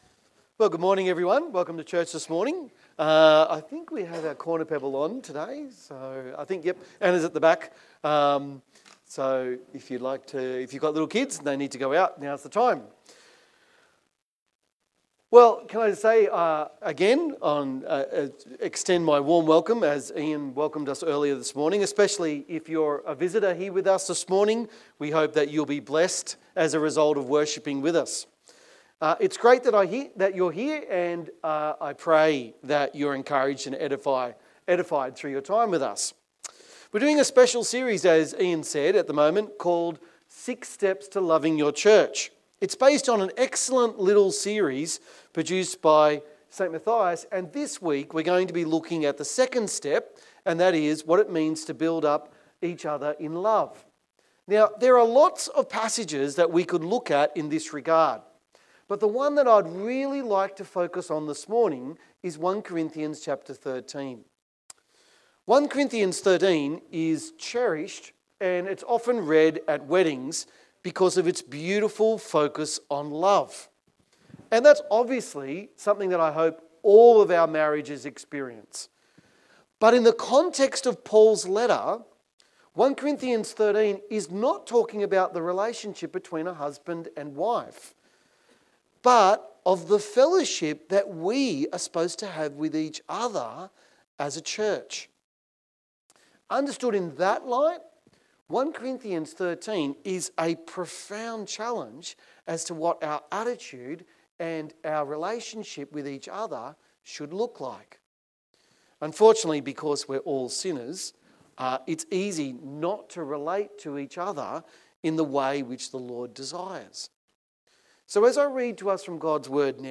Text: Psalm 133:1-3; 1 Corinthians 12:31-13:13 Sermon